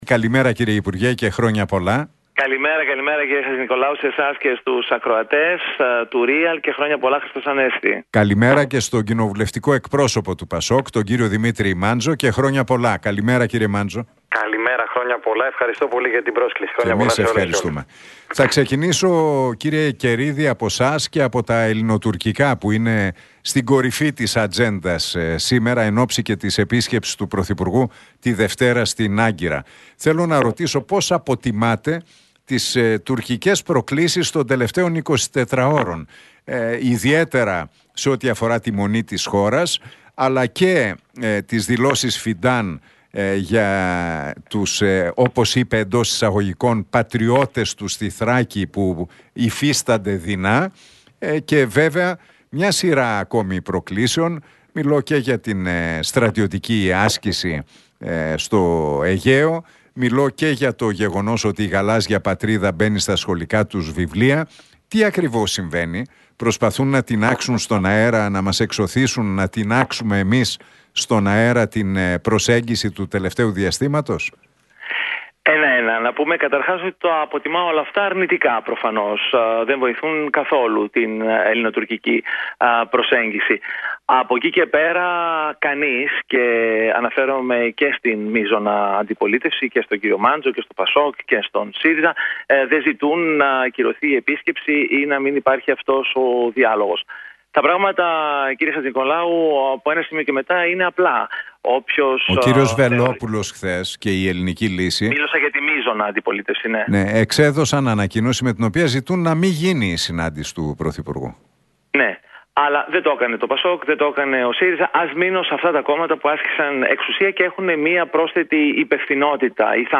Τα ξίφη τους διασταύρωσαν στον αέρα του Realfm 97,8 σε ένα ραδιοφωνικό debate, στην εκπομπή του Νίκου Χατζηνικολάου ο υπουργός Μετανάστευσης και Ασύλου,